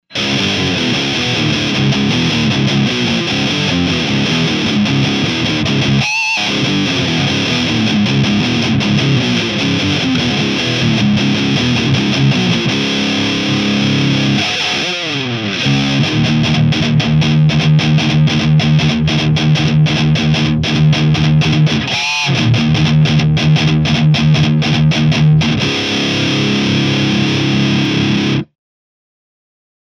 FERNANDESのプックアップVH-401とEMG 81のサンプルサウンドです。
アンプはJCM2000 DSL100です。
EMG 81